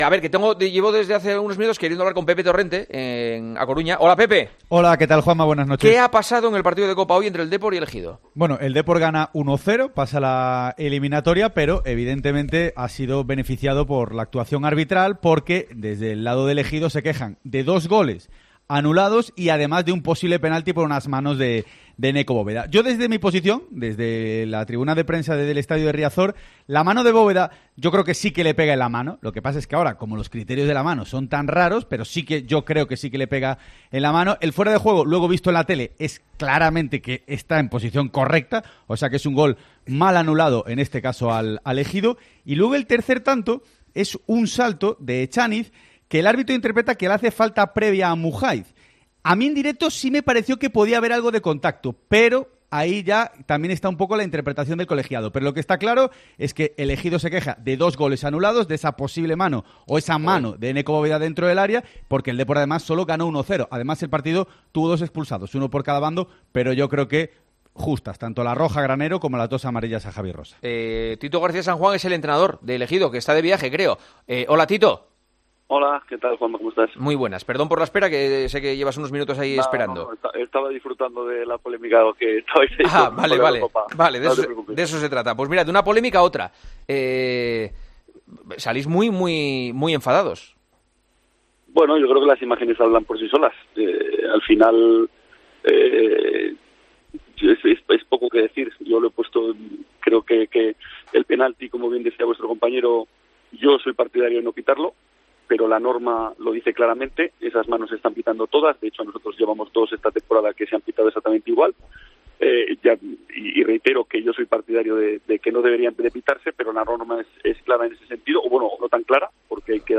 en El Partidazo de COPE mientras que viajaba en autobús desde tierras gallegas